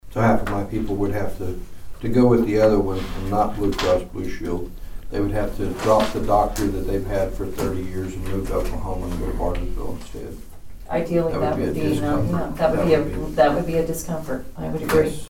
The Board of Nowata County Commissioners held a regular meeting on Monday morning at the Nowata County Annex.
District Three Commissioner Troy Friddle explained the advantage of keeping their current insurance.